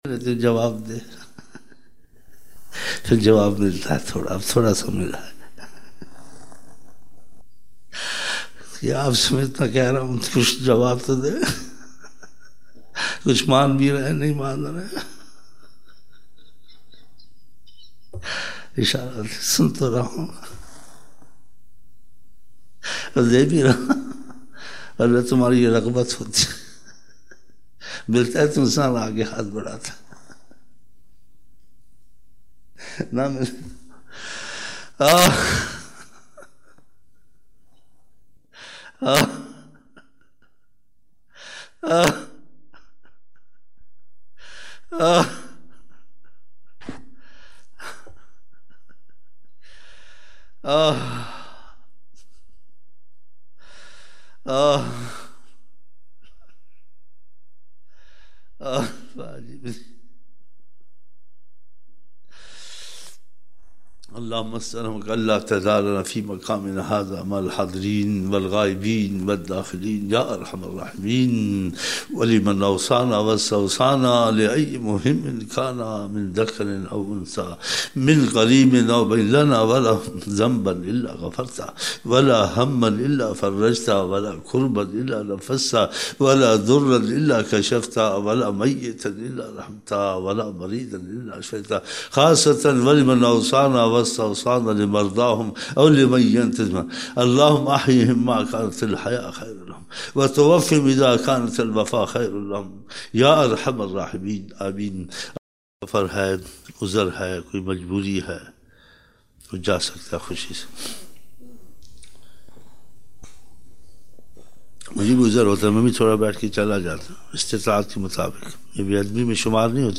25 November 1999 - Fajar mehfil (17 Shaban 1420)